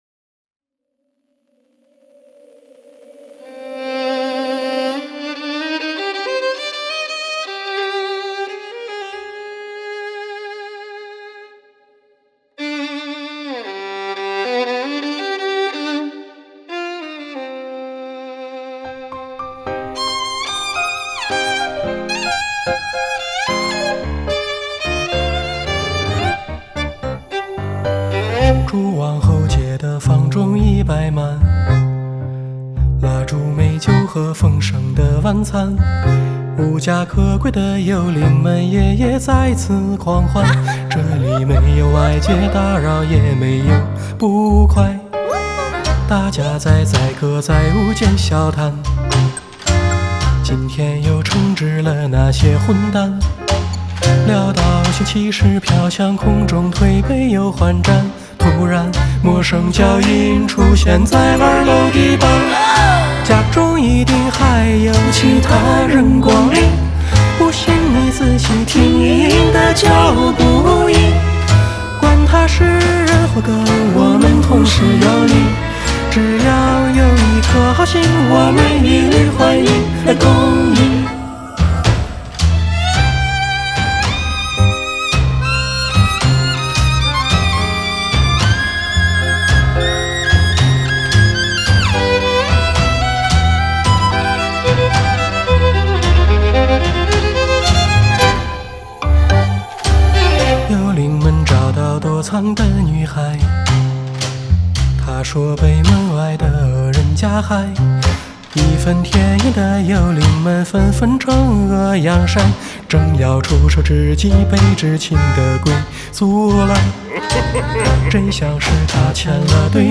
Here I would like to share with you a song that I composed - "Ghost Party", a tango-style song that incorporates elements of musical drama.